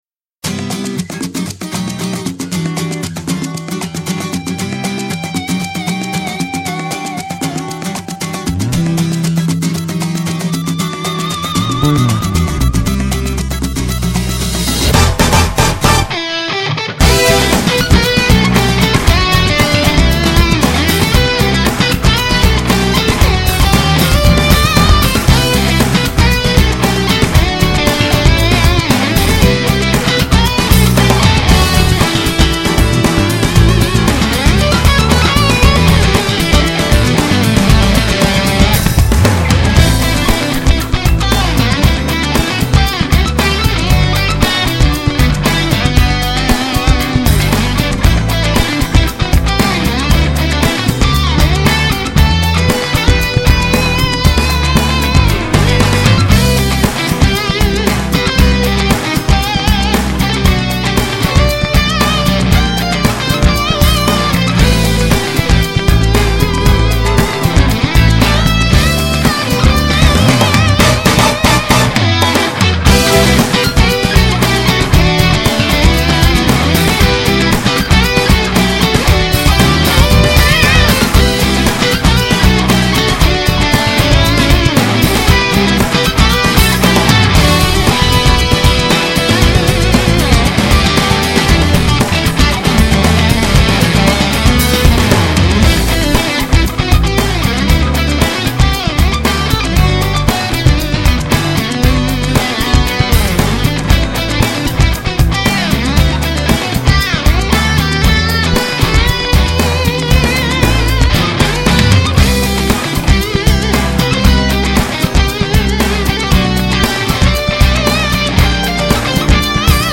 진짜 여름 바닷가에서 들으시면 & 드라이브하시면서 들으시면 짱일 곡입니다.